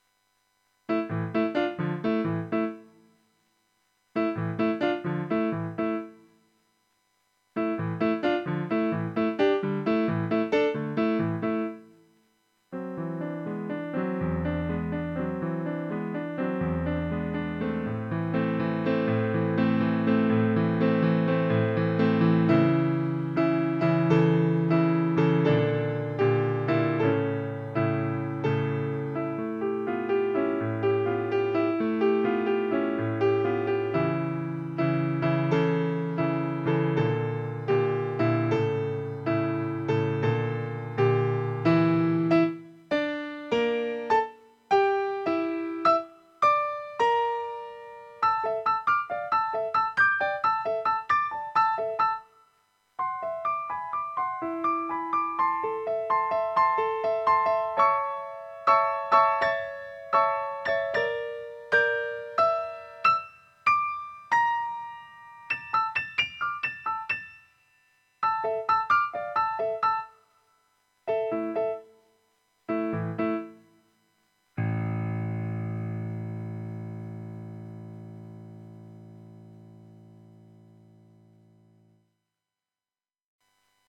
Yamaha P-125